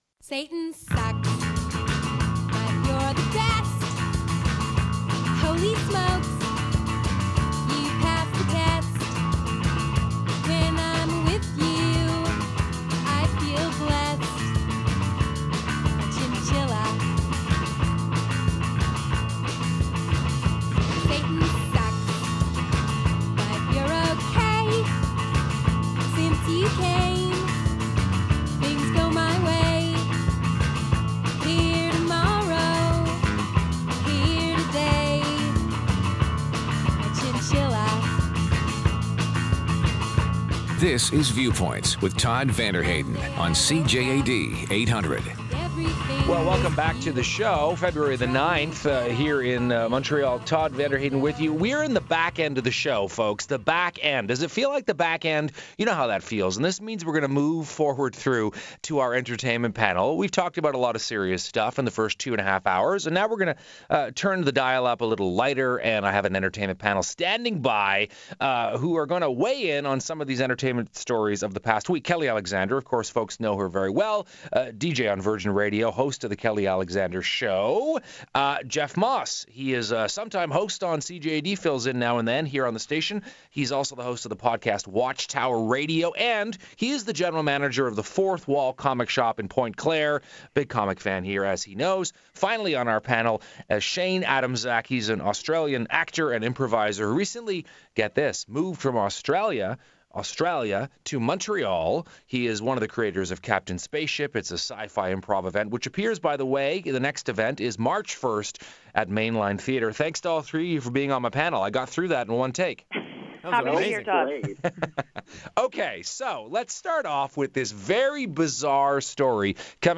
If you missed my “appearance” on the CJAD entertainment panel over the weekend, you can have a listen to the files here! The show was rapid fire and super fun, we talked about Valentine’s Day, Anne Of Green Gables, Women on leashes and much more…check it out.